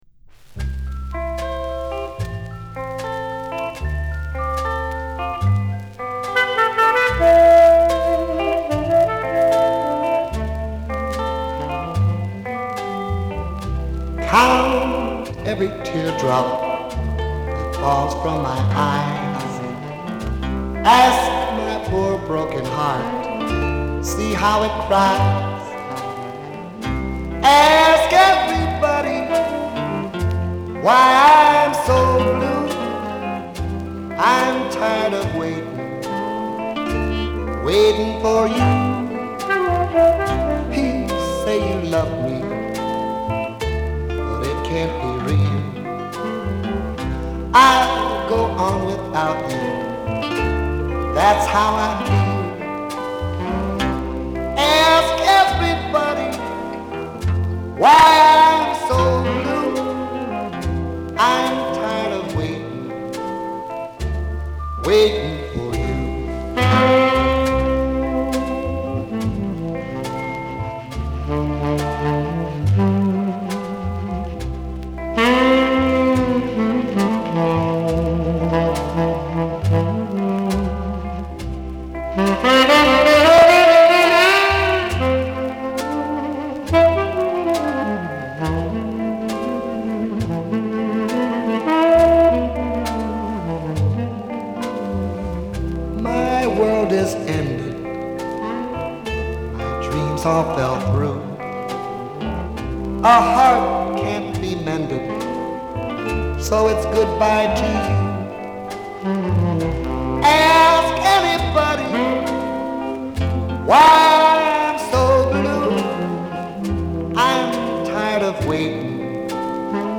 B面はバラード・ナンバーを収録。艶やかな歌声を聴かせてくれる。